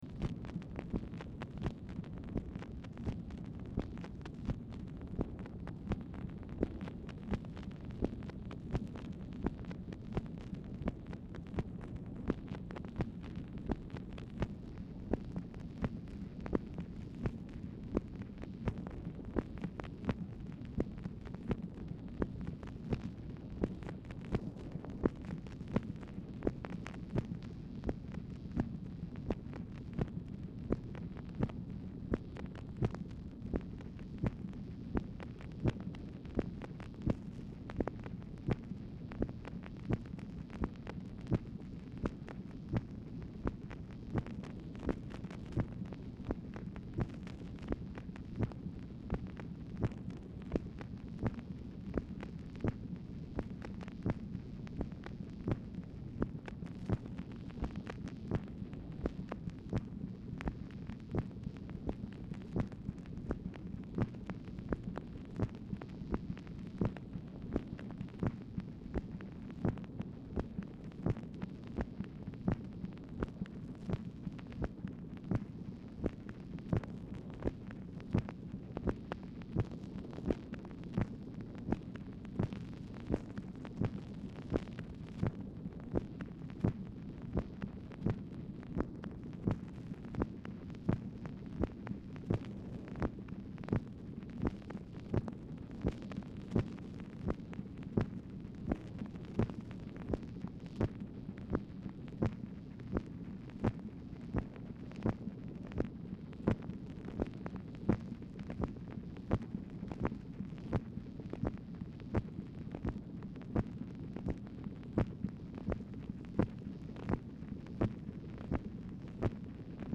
Telephone conversation # 8124, sound recording, MACHINE NOISE, 6/10/1965, time unknown | Discover LBJ
Format Dictation belt
Speaker 2 MACHINE NOISE Specific Item Type Telephone conversation